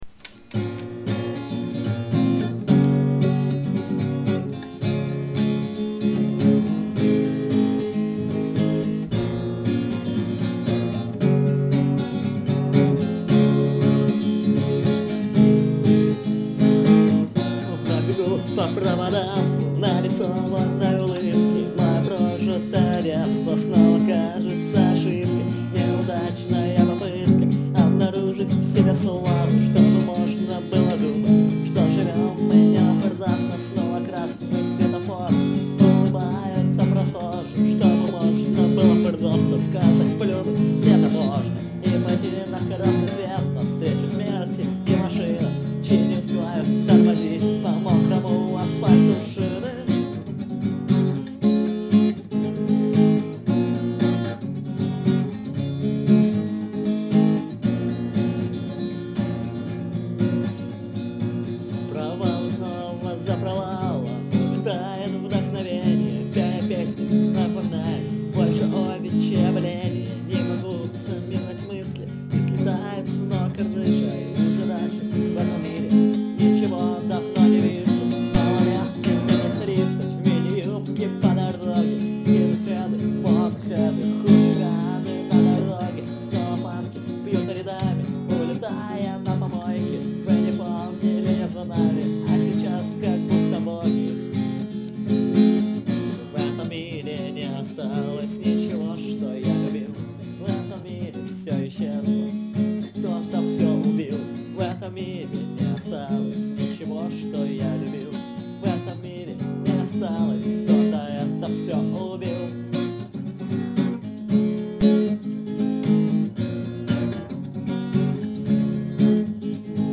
Категория: Акустика